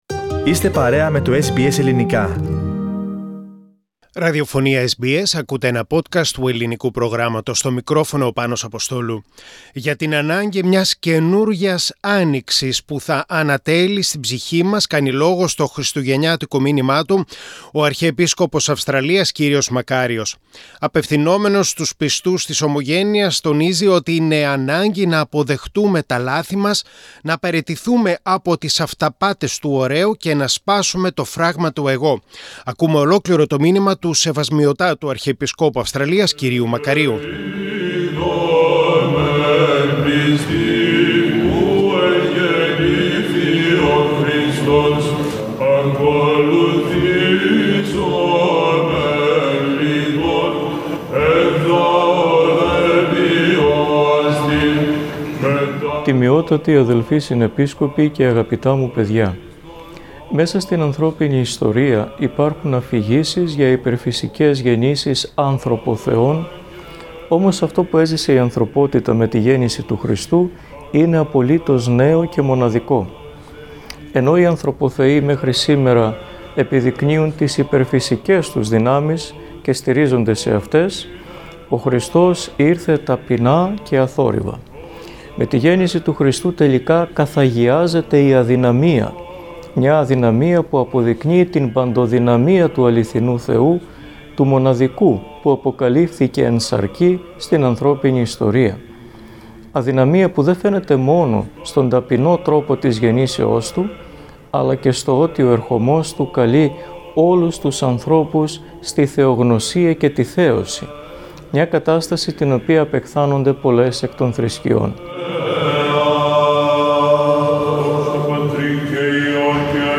Το Χριστουγεννιάτικο μήνυμα του Αρχιεπισκόπου Αυστραλίας, κ Μακαρίου.
2021 Christmas Message from His Eminence Archbishop Makarios of Australia.